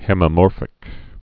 (hĕmĭ-môrfĭk)